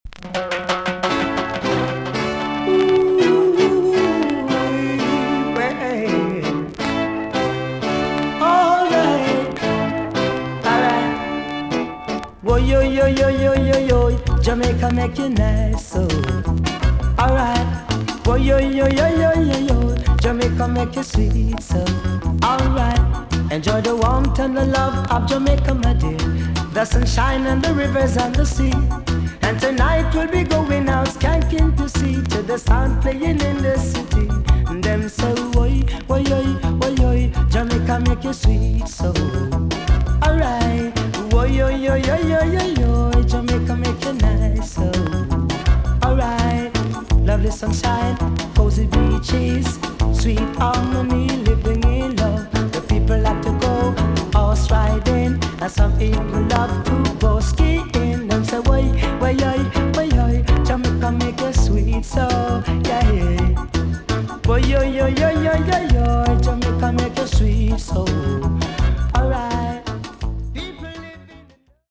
TOP >80'S 90'S DANCEHALL
VG+ 少し軽いチリノイズが入ります。